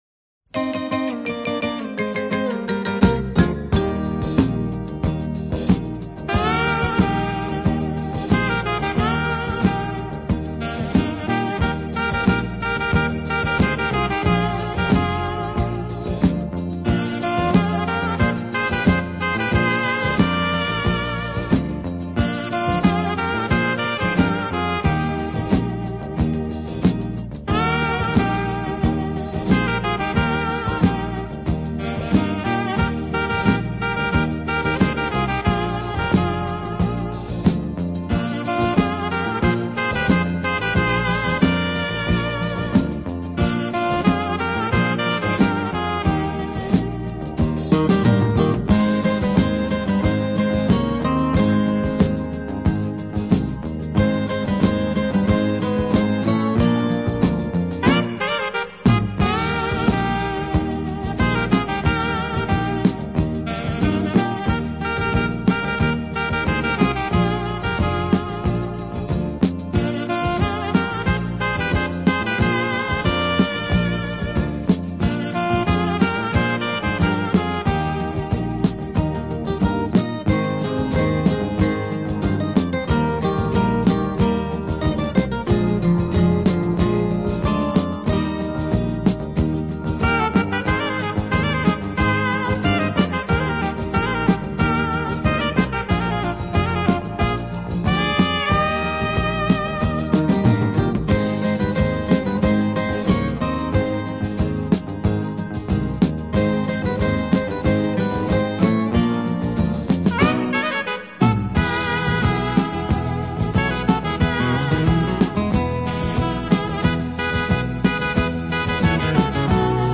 Жанр: Instrumental Saxophon